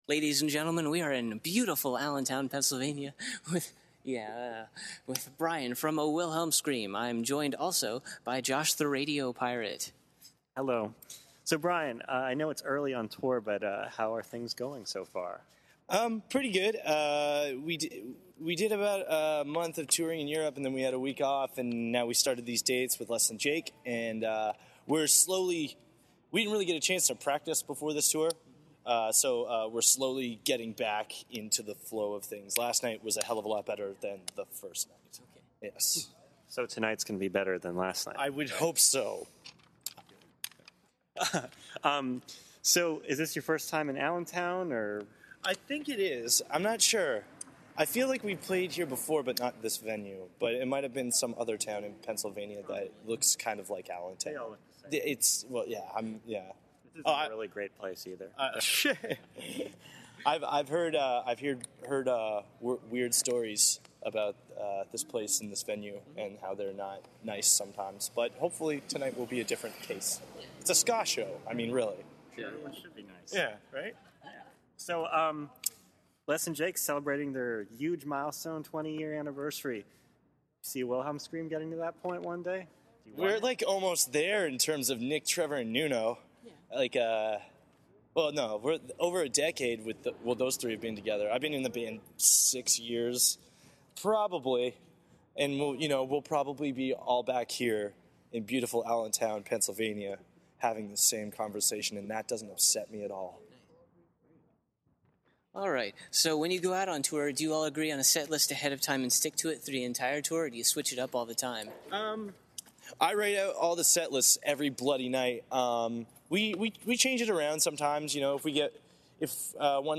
Exclusive: A Wilhelm Scream Interview
The long-running and highly influential band, A Wilhelm Scream, stopped at Allentown’s Crocodile Rock Cafe on Wednesday, September 12th while they provided support for Less Than Jake’s 20th Anniversary Tour.
If you listen closely, you can hear one of Allentown’s finest residents inserting himself into the interview!
interview-a-wilhelm-scream.mp3